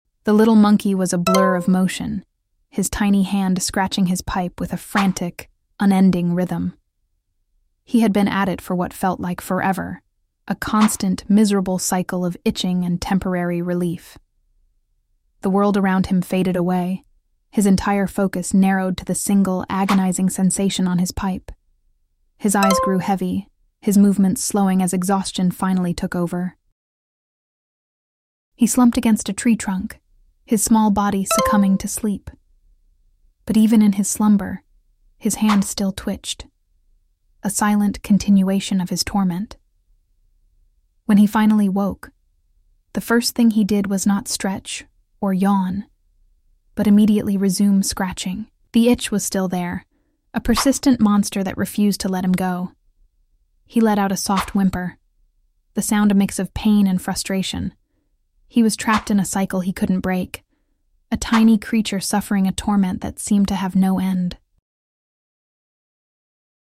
Gosh poor baby crying when sound effects free download